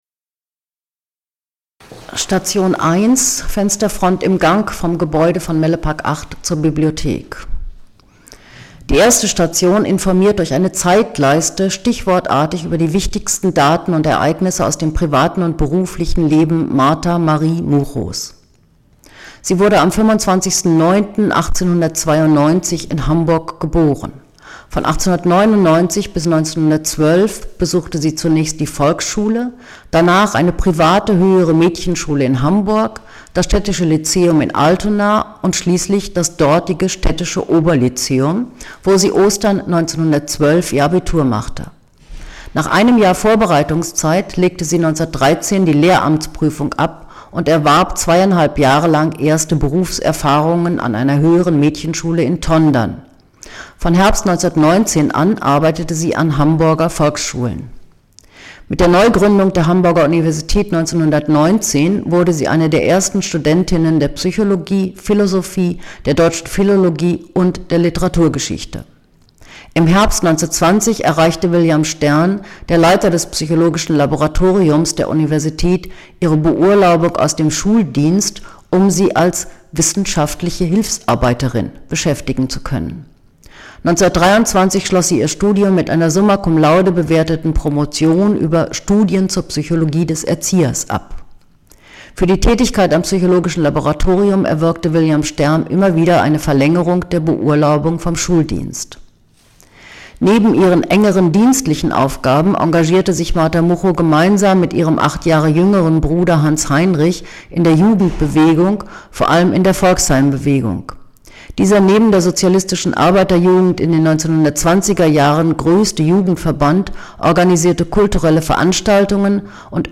Audioguide: Station 1)